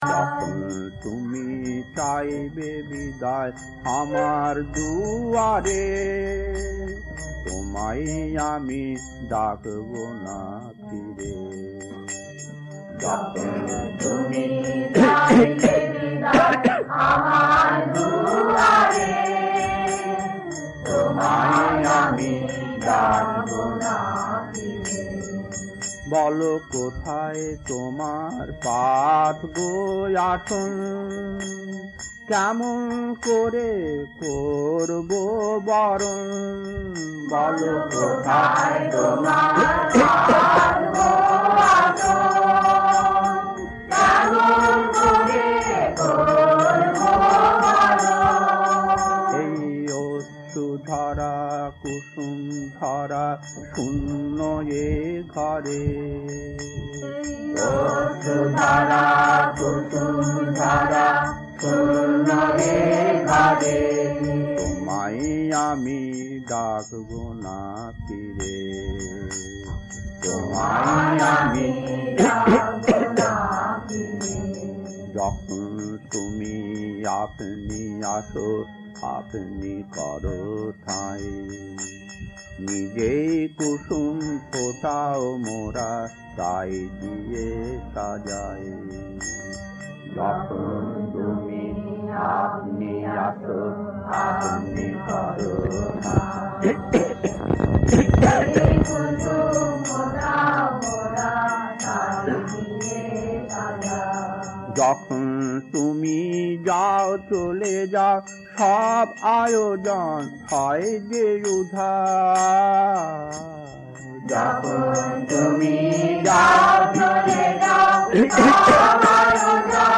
Kirtan A5-2 Puri December 1975 1.